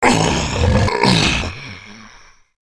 gnoll_mage_die.wav